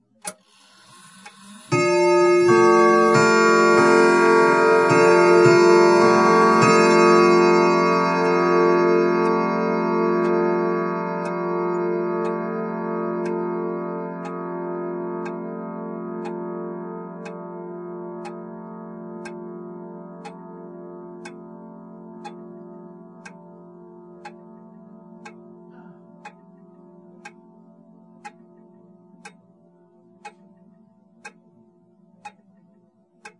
祖父钟 " 祖父钟报时二分之一
描述：一个祖父钟敲击半小时。 由于这是在时钟内部录制的，所以你还能听到时钟机制的所有其他环境噪音，包括滴答声
Tag: 滴答 古董 钟声 罢工 机械 迷人 祖父 时钟 弗利